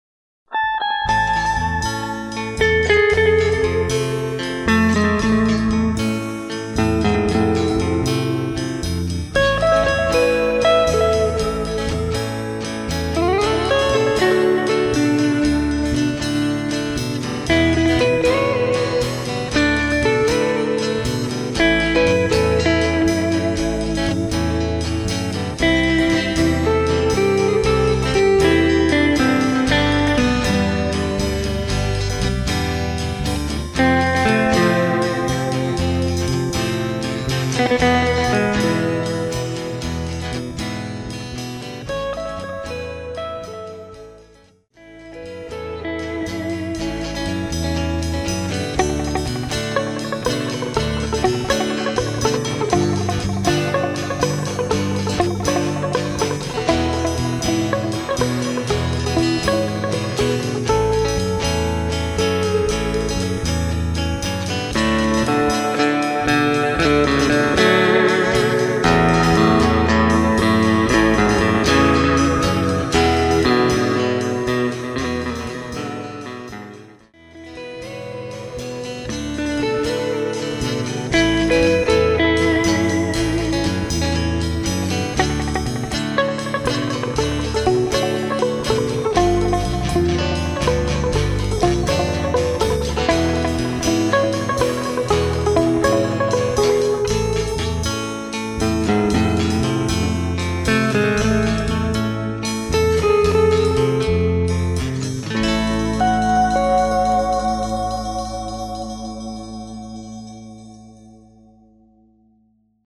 Same rehearsal day.
First time the rhythm guitar plays the song, and I think he throws a minor at one point instead
I added a little bit of reverb.